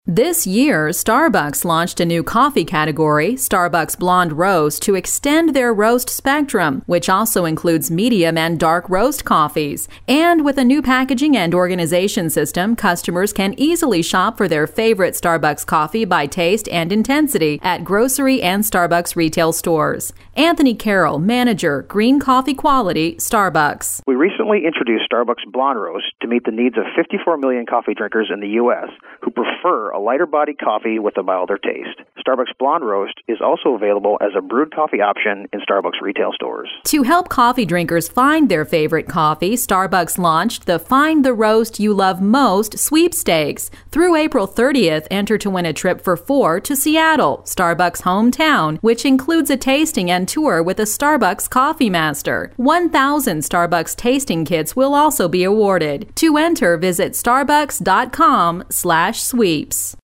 March 26, 2012Posted in: Audio News Release